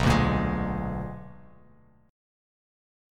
BmM13 chord